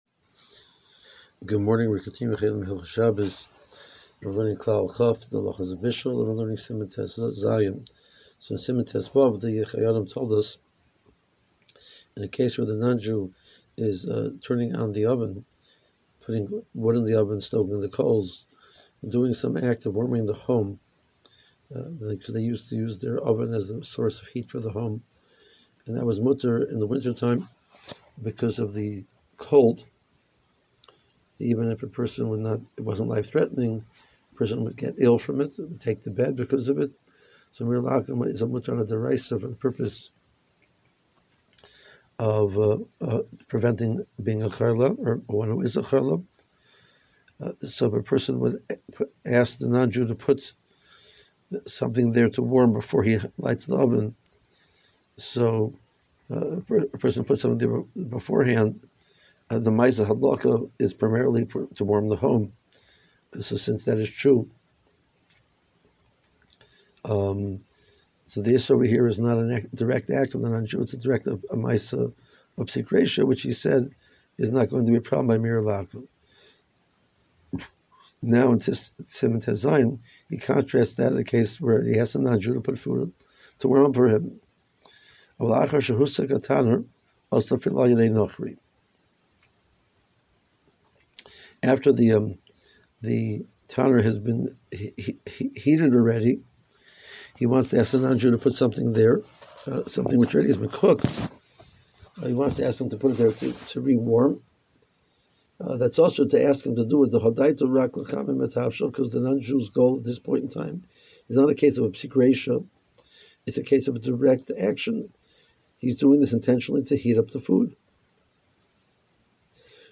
• 5 Min. Audio Shiur Including Contemporary Poskim